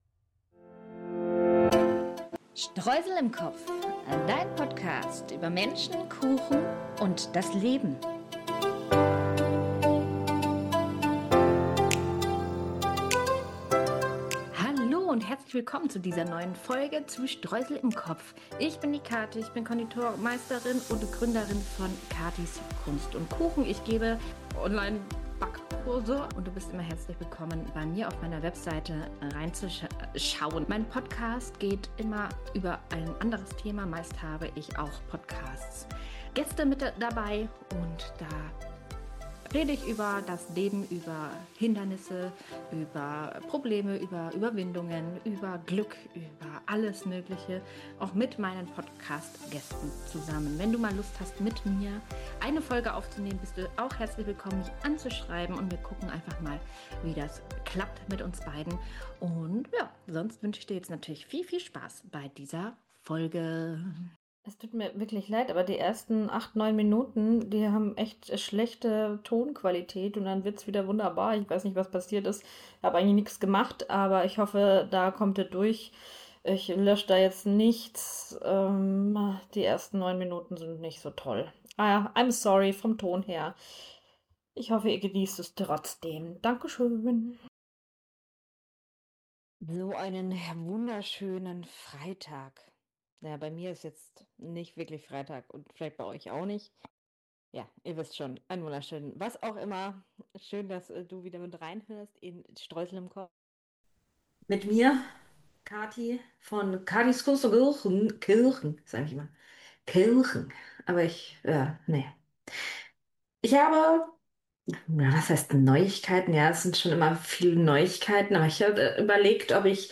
Beschreibung vor 6 Monaten Die letzten Wochen haben mich geschafft :D Hört selber ;) Sorry, die ersten Minuten sind echt mit einer schlechten Tonqualität!